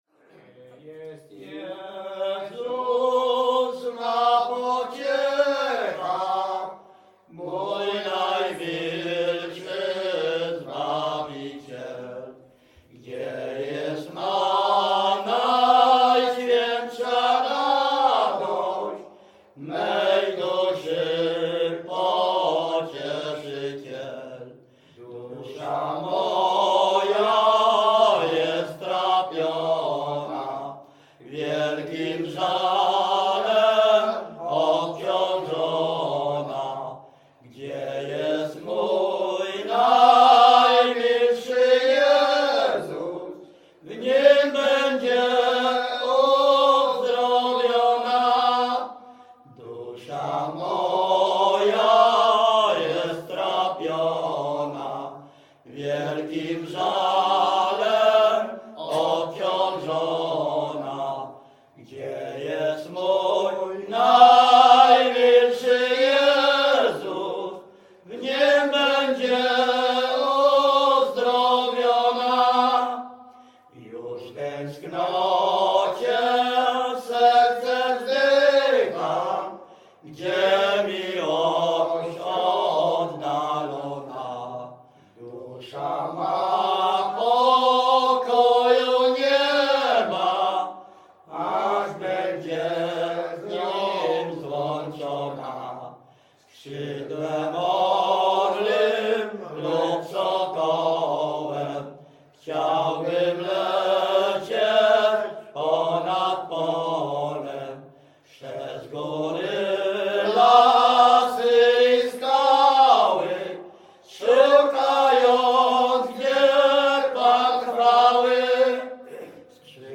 Śpiewacy z Ruszkowa Pierwszego
Wielkopolska, powiat kolski, gmina Kościelec, wieś Ruszków Pierwszy
śpiewają ze sobą od młodych lat, mają bardzo bogaty repertuar pieśni (zwłaszcza religijnych) w miejscowych wariantach melodycznych
Pogrzebowa
Array nabożne katolickie pogrzebowe